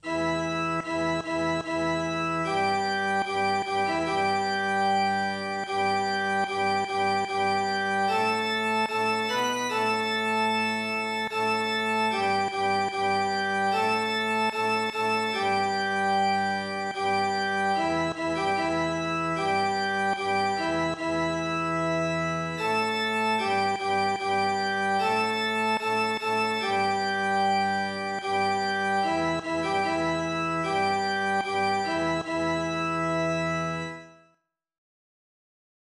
alto.wav